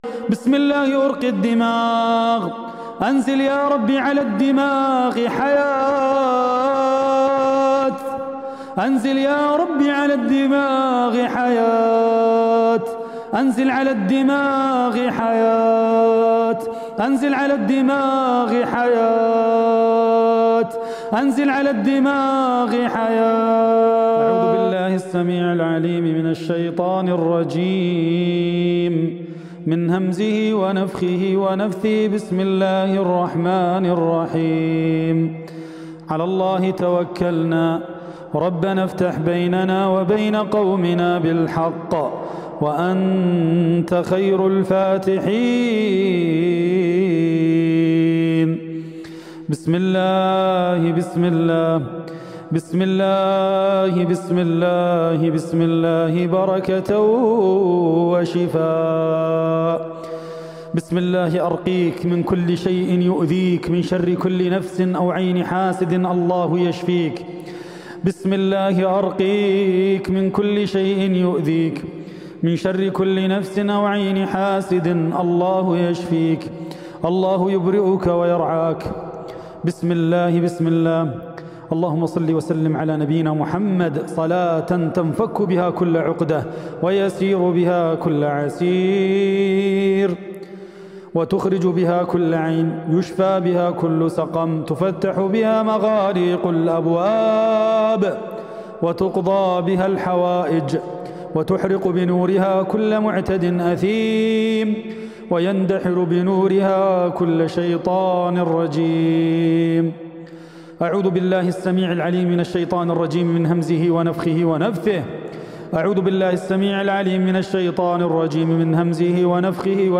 রুকইয়াহ অডিও